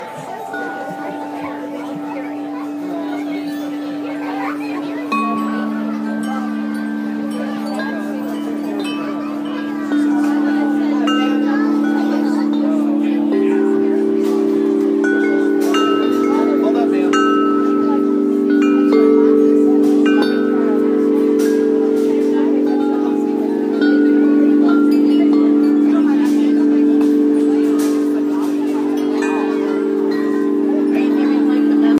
风铃 " 竹制风铃1
描述：在一个阳光明媚的春天早晨，花园里的竹风铃。
标签： 场记录 风铃
声道立体声